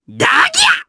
Lakrak-Vox_Attack1_jp_b.wav